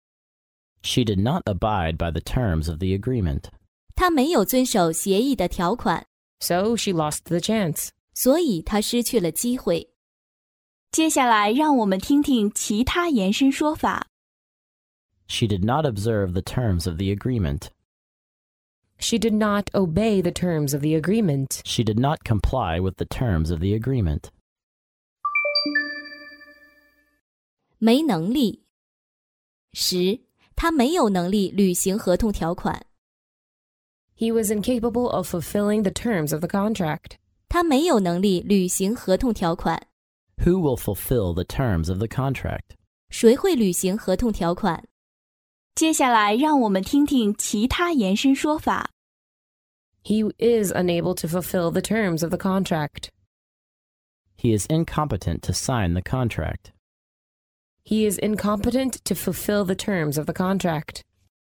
真人发音的朗读版帮助网友熟读熟记，在工作中举一反三，游刃有余。